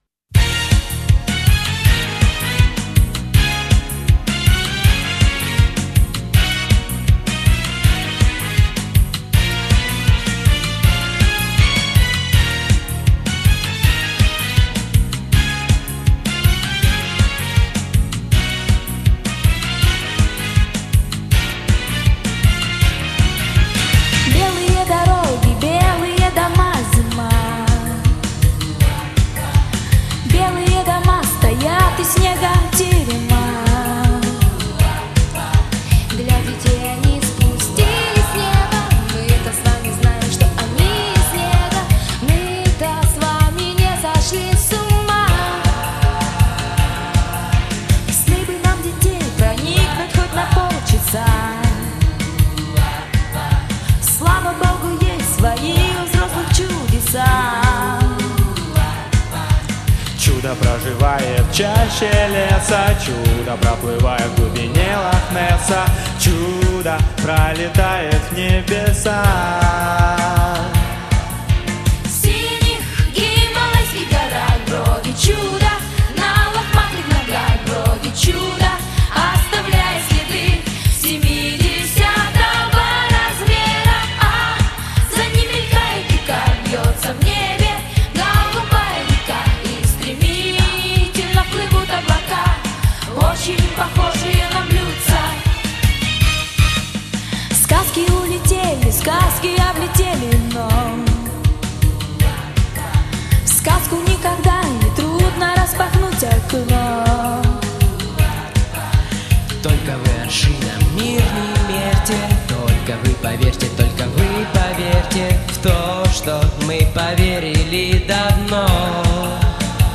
Народный вариант